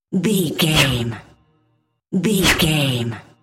Airy swish pass by fast
Sound Effects
Fast
futuristic
sci fi